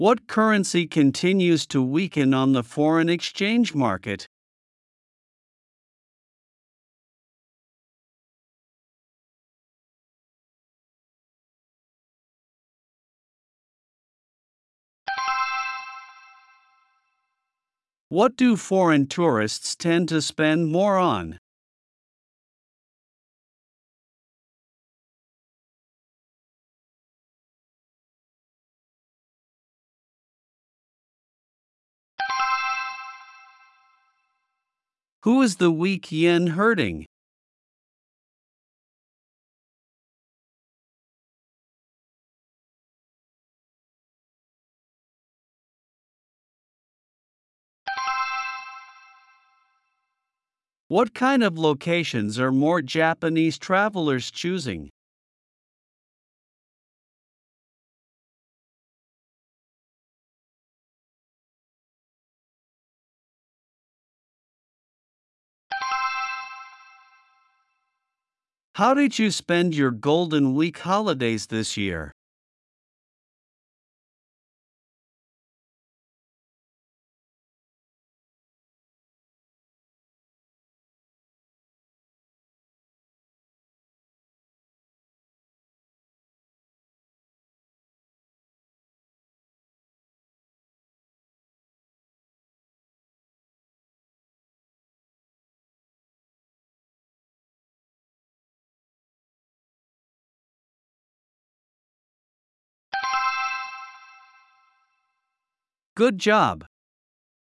プレイヤーを再生すると英語で5つの質問が1問ずつ流れ、10秒のポーズ（無音部分）があります。
10秒後に流れる電子音が終了の合図です。
Only Q5 in the B2 level version allows for a 30-second response time.
【B2レベル：10秒スピーチｘ4問＋30秒スピーチｘ1問】